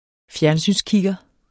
Udtale [ ˈfjæɐ̯nˌsyns- ]